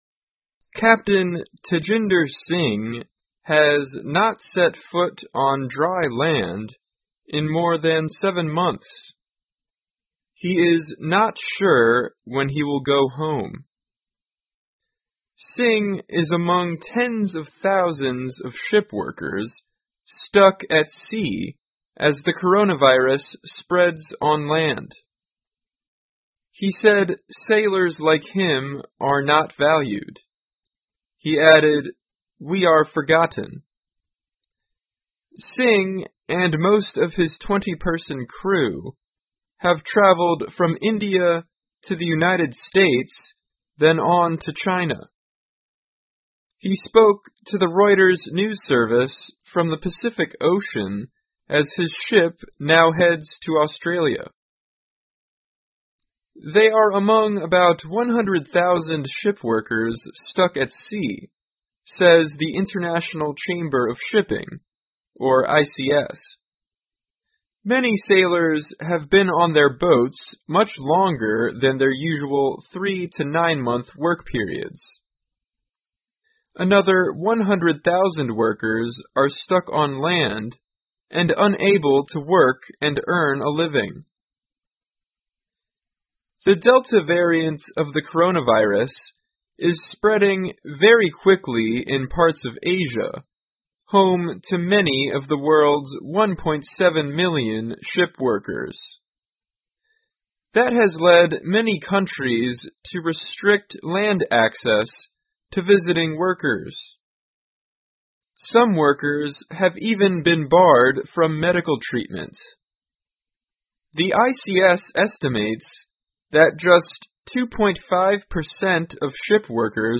VOA慢速英语--船员被困在海上，疫情期间补给受到威胁 听力文件下载—在线英语听力室